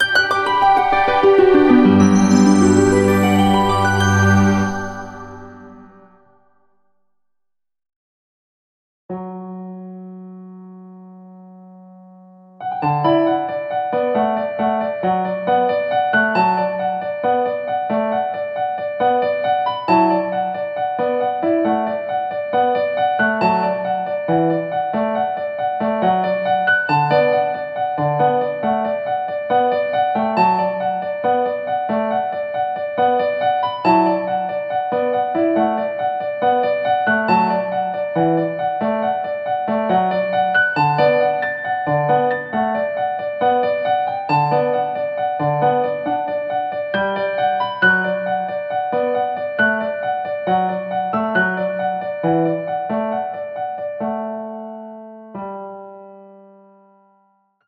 【入場合図音とBGM】